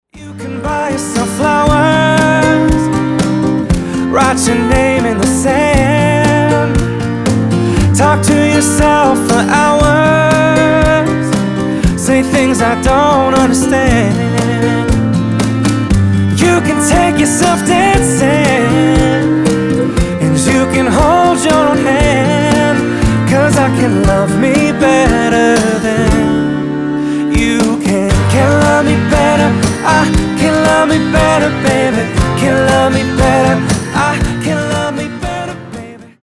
• Качество: 320, Stereo
гитара
мужской голос
Cover
акустика